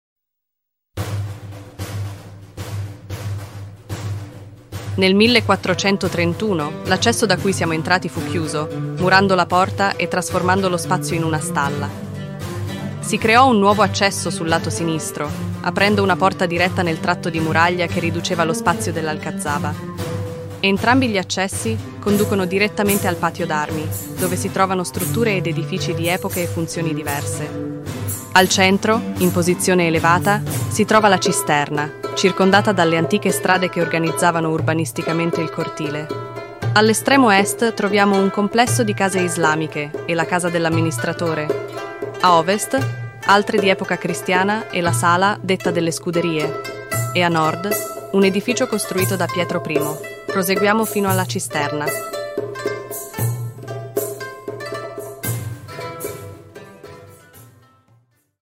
Ruta audioguiada
audioguia-italiano-qr4.mp3